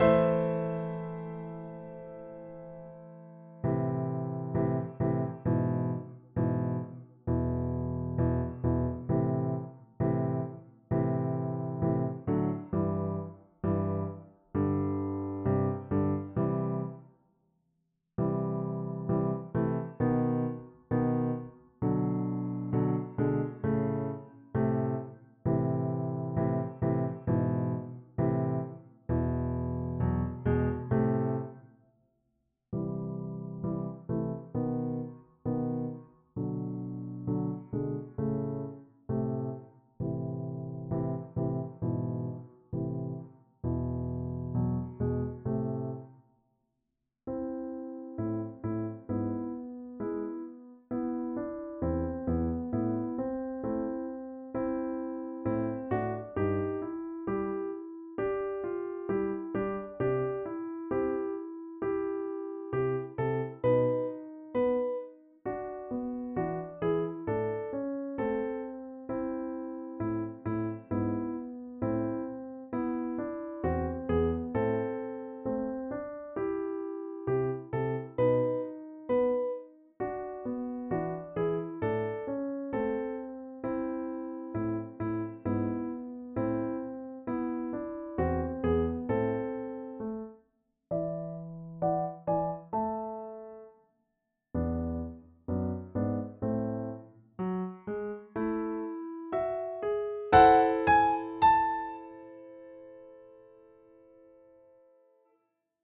in A major